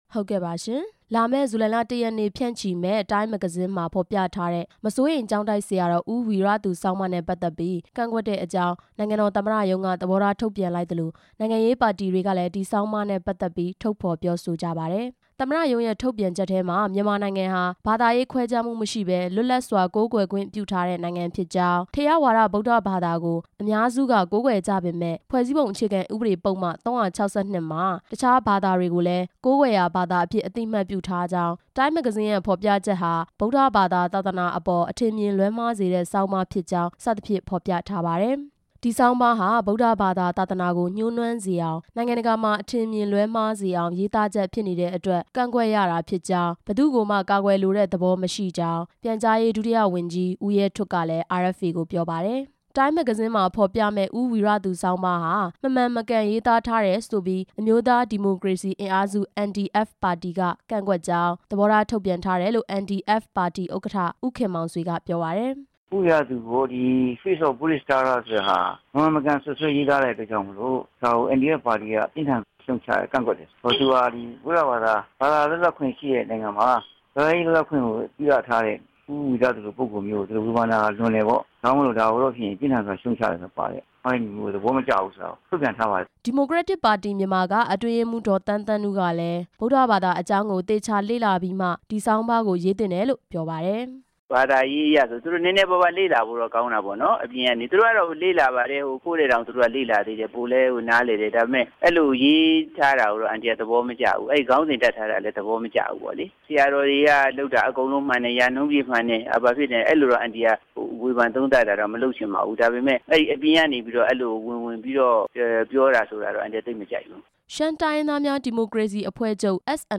နိုင်ငံရေးပါတီတွေရဲ့ သဘောထားအမြင်တွေကို စုစည်းတင်ပြချက်